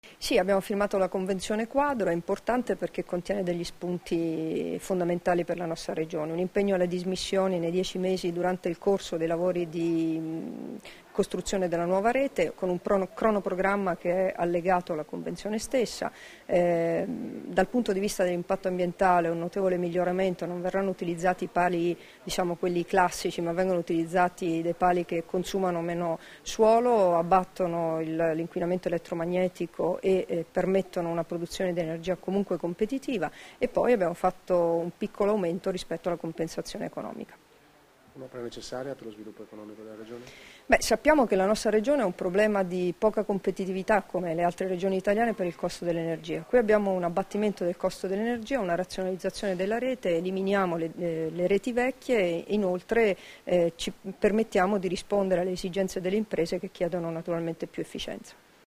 Dichiarazioni di Debora Serracchiani (Formato MP3) [940KB]
rilasciate a margine della firma della Convenzione quadro tra il Friuli Venezia Giulia e la Terna Rete Italia S.p.A. per la realizzazione dell'elettrodotto Udine Ovest-Redipuglia, a Udine l'11 ottobre 2013